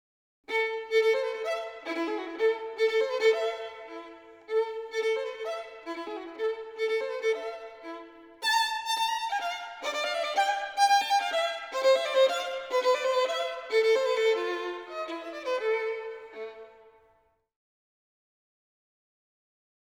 Geige (Violine)
Streichinstrumente
Mit der Geige kannst du die höchsten Töne spielen und vor allem kannst du mit ihr überall mitspielen.
01-Violine.mp3